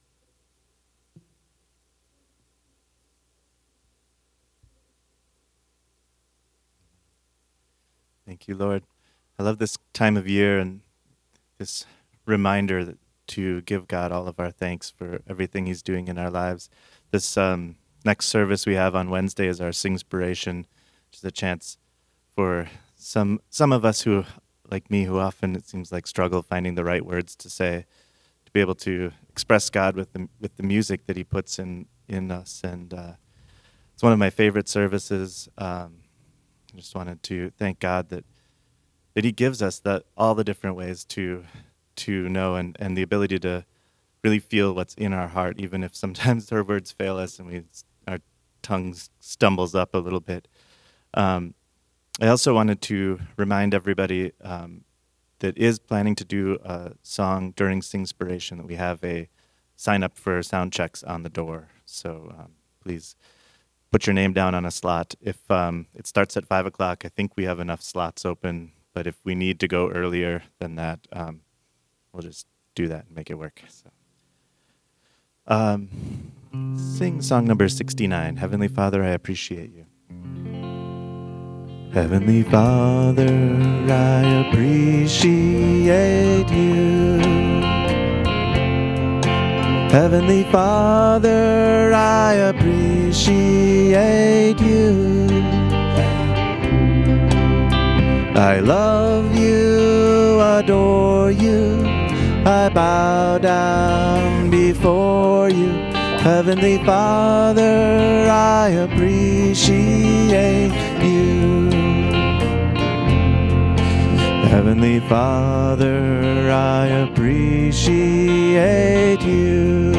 Sunday Service 11/21/21